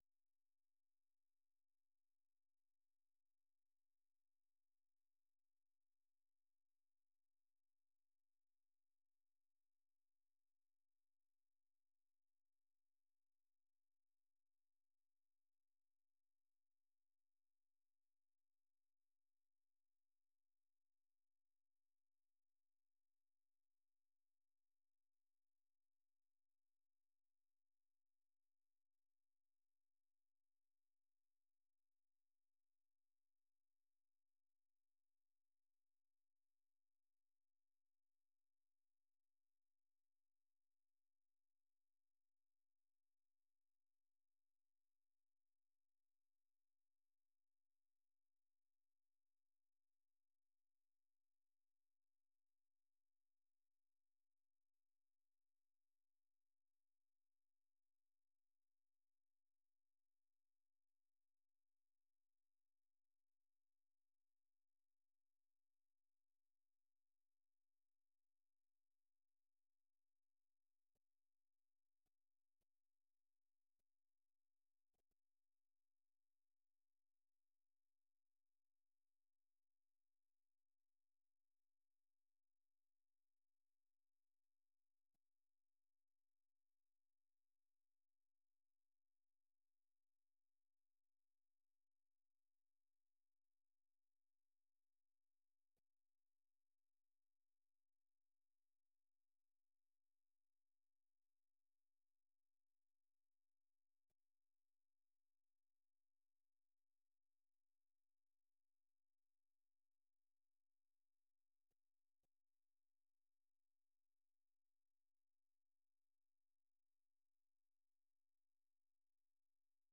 Listen Live - 생방송 듣기 - VOA 한국어